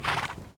snort.ogg